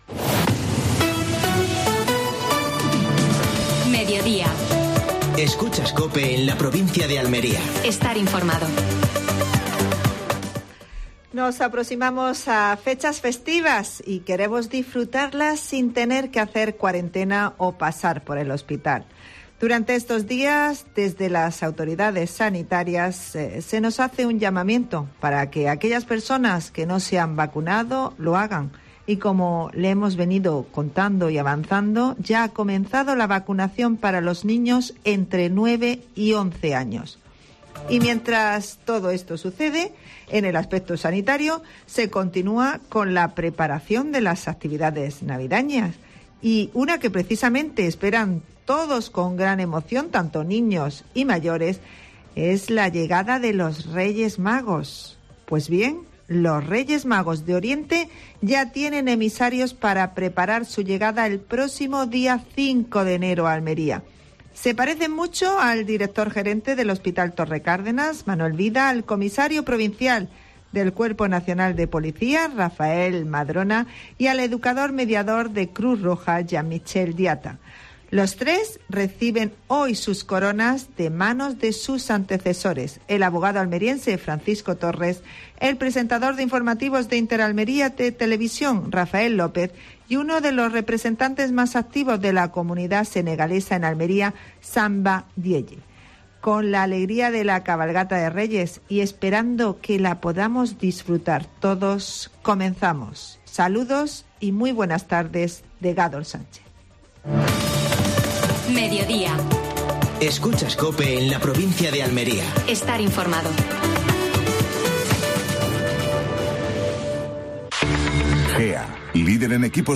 Actualidad en Almería. Entrevista a Fernando Giménez (diputado provincial).